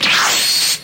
Teleport Sound